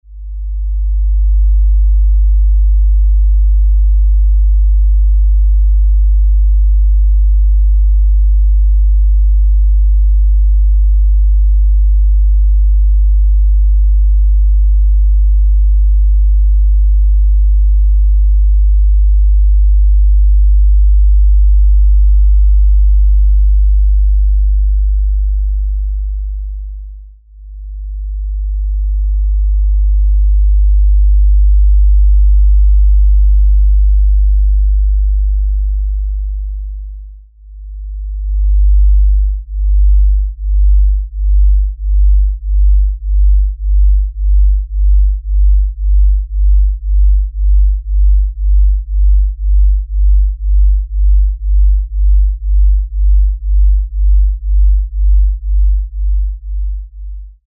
This simulated audio file illustrates the audible interactions experienced within the installation.
Left channel : 60 seconds at 50Hz | Right channel : 20 seconds at 50 Hz, 20 sec at 49.9 Hz, 20 sec at 48 Hz.
Note: this will not play correctly on most devices; larger desktop speakers, headphones or stereo is recommended to reproduce the low frequency.